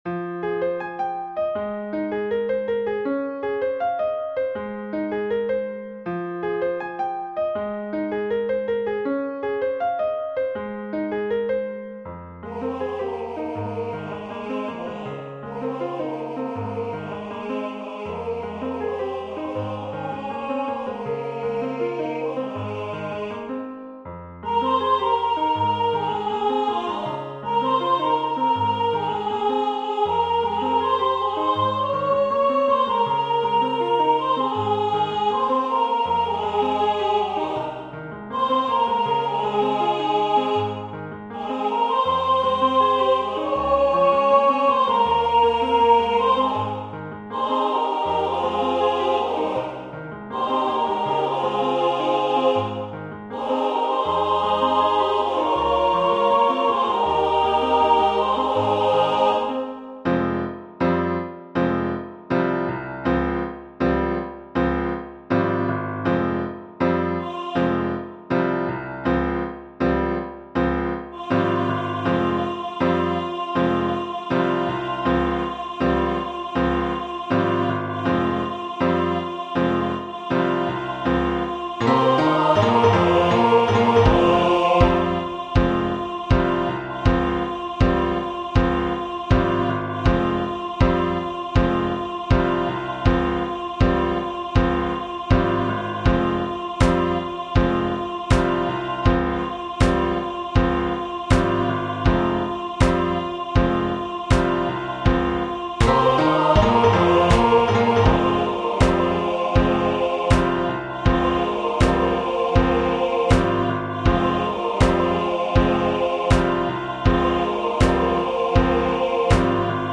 Arrangement pour chœur
Genre : R’n’B / Rap
français Voix : SAT Difficulté